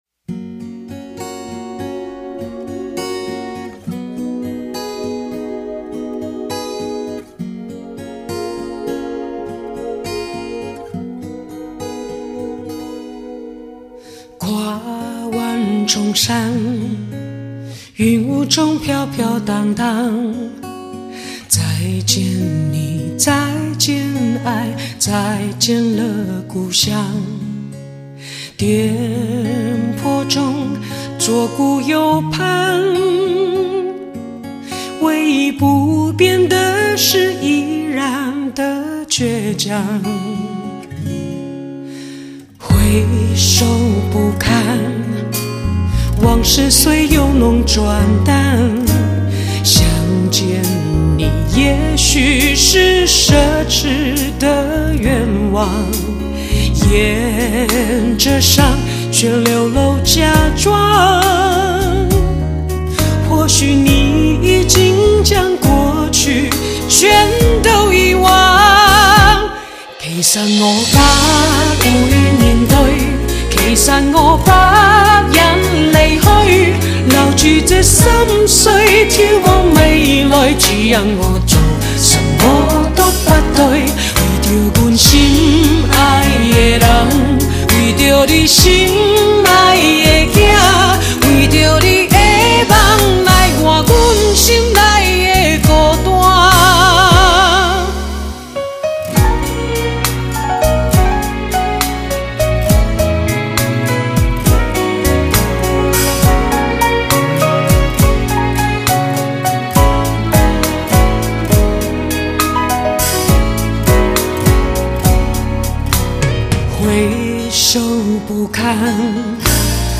每一曲音色都妩媚多姿，让人回味且沉醉在迷人的温柔音乐之中，每一首曲子都有属于自己的